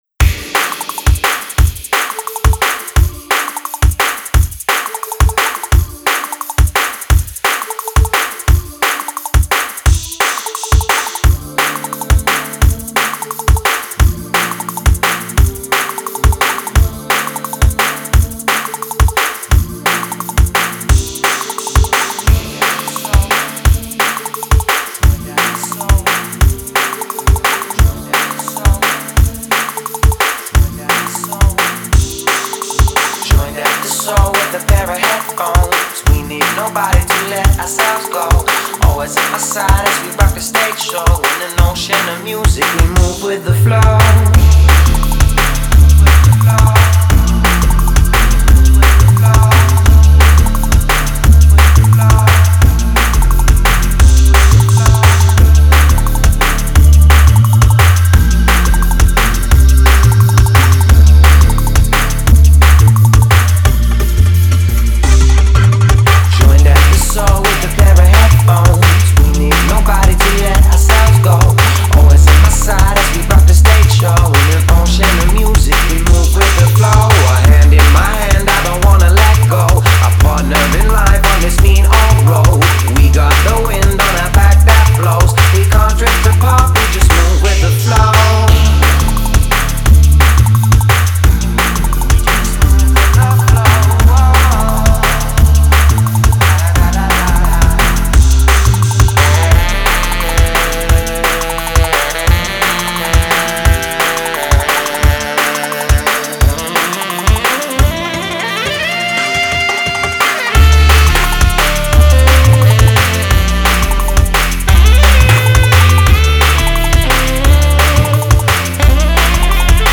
smooth